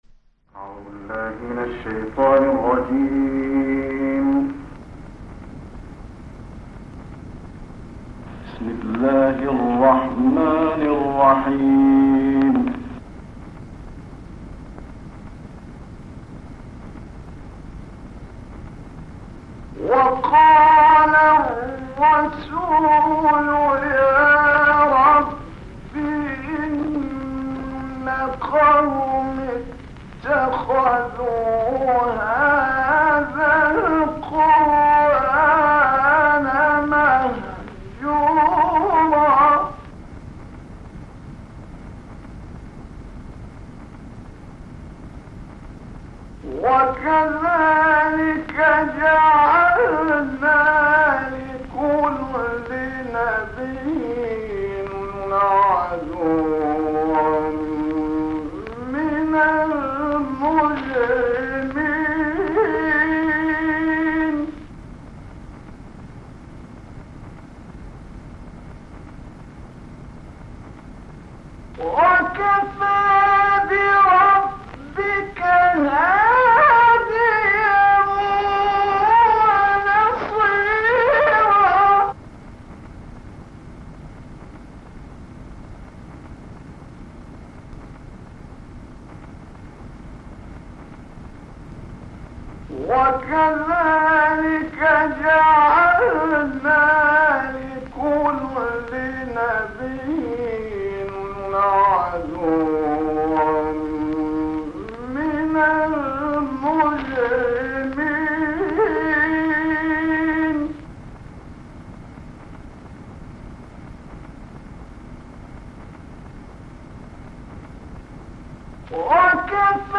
تلاوتی از شیخ محمد رفعت/ آیاتی از سوره مبارکه فرقان+صوت
شیخ محمد رفعت از قاریان نامدار جهان اسلام و کشور مصر است که در این گزارش تلاوتی از وی شامل ایاتی از سوره مبارکه فرقان تقدیم می‌شود.